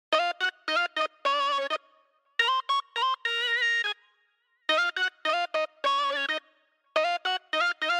ULY_KIT4_105_lead_talkbox_vocoder_A#min